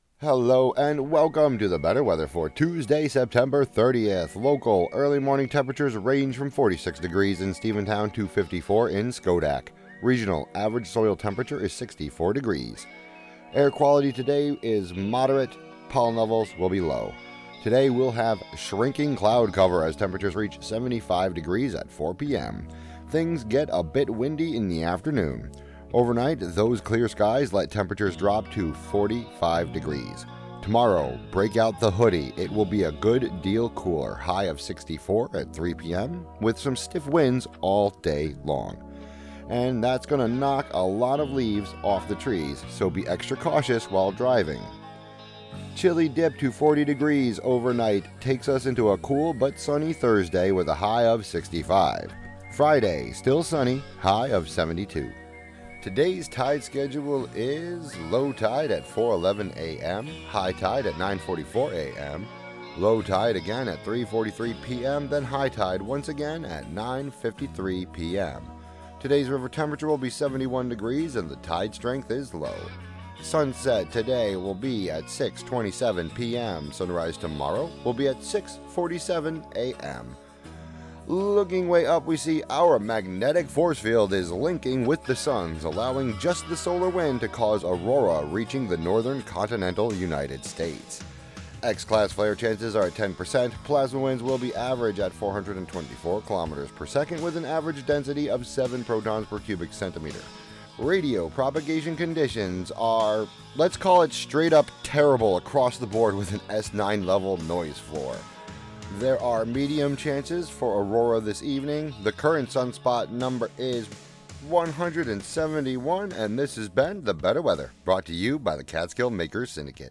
brings listeners meteorological predictions, pollen counts, Hudson River water temperatures, space weather, and more on WGXC 90.7-FM.